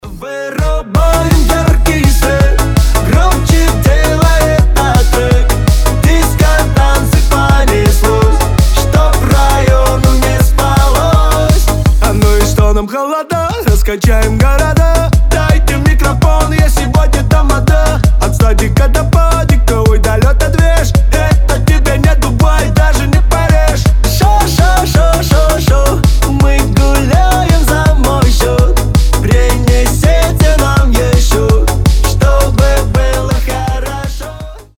позитивные
веселые
энергичные
быстрые